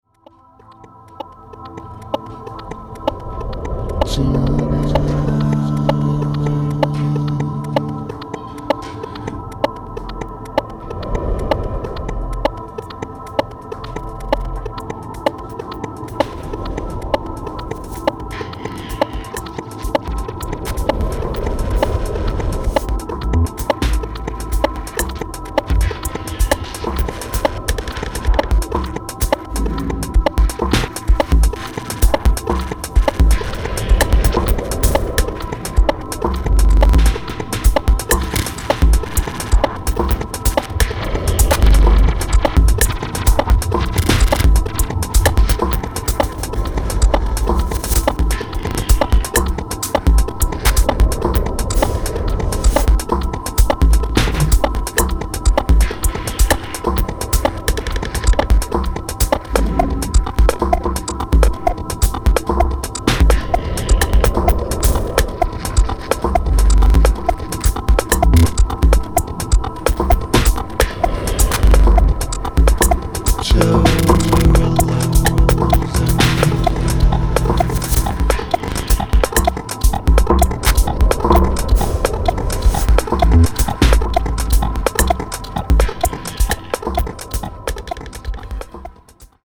妖艶に蠢くアヴァンギャルドな音像、あくまでもファンキーでしなやかなハウスビート。